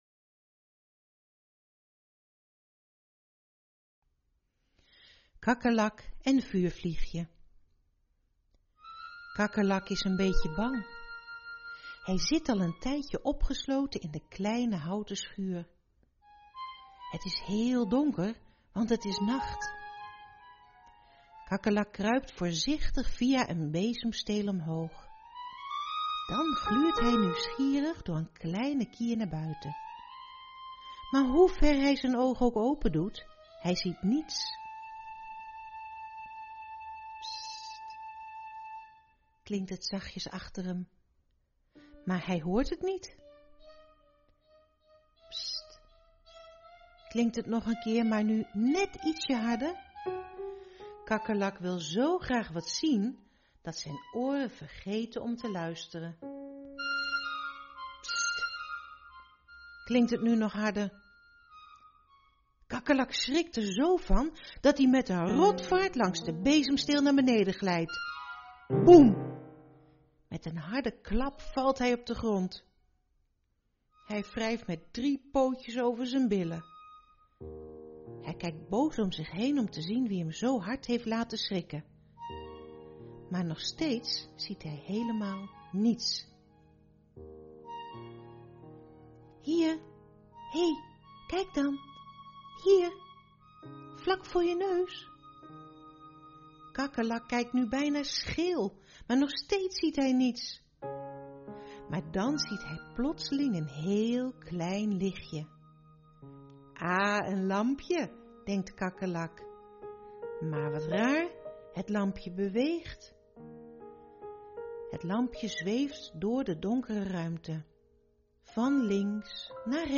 download Het Lekker Puh Luisterboek deel 1 --- Klik hierboven voor het downloaden!